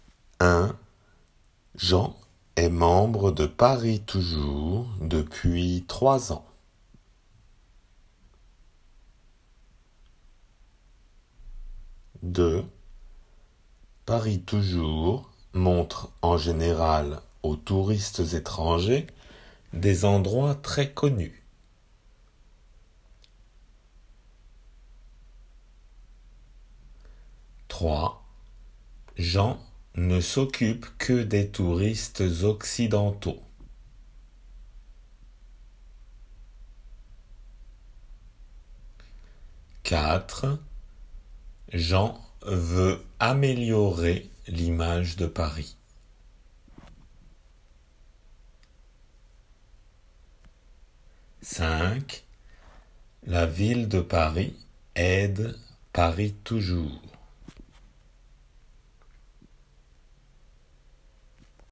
質問文